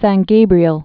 (săn gābrē-əl)